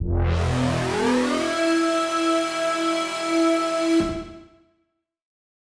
Index of /musicradar/future-rave-samples/Poly Chord Hits/Straight
FR_ProfMash[hit]-E.wav